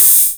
KR55_OH_03.wav